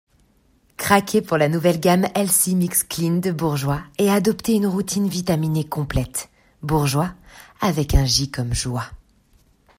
Voix off
20 - 40 ans - Mezzo-soprano
voix-grave